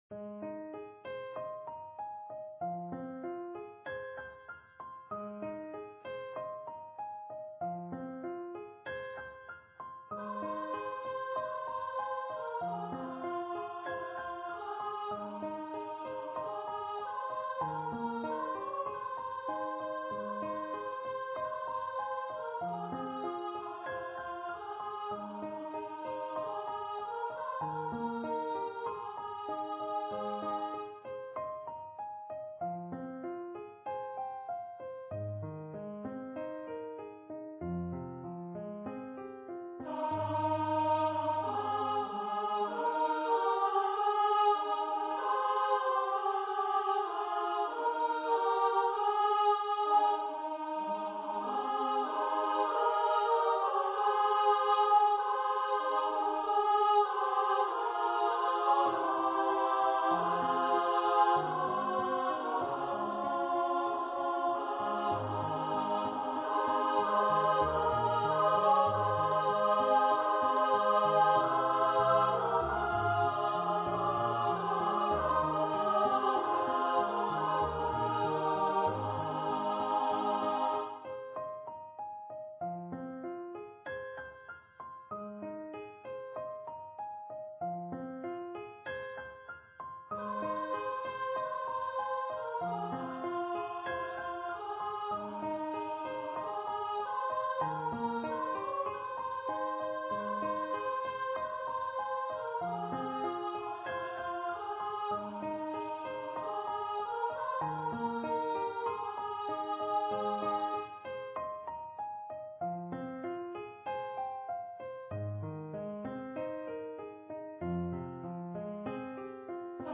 for female voice choir
Choir - 3 part upper voices